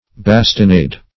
Bastinade \Bas`ti*nade"\, n.